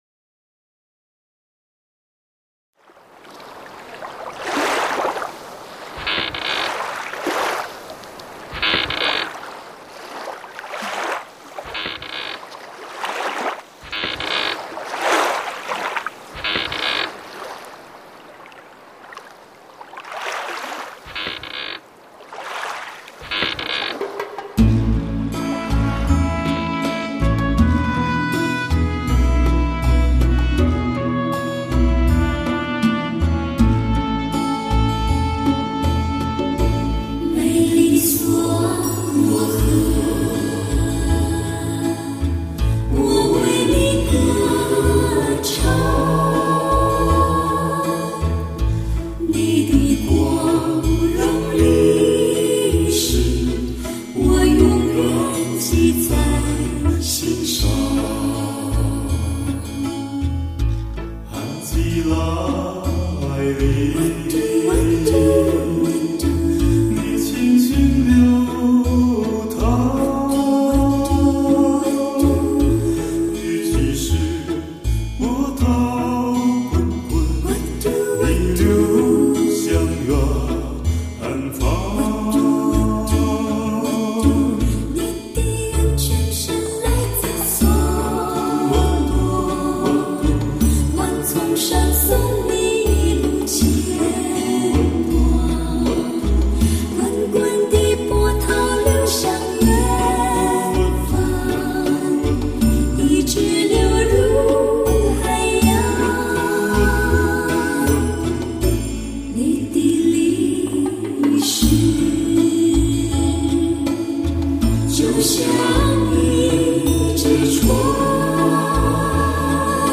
这首歌由印度尼西亚业余作曲家格桑·马尔托哈尔托诺（Gesang Martohartono）于1940年所写成，风格来自当地的受葡萄牙音乐影响的Keroncong风民歌。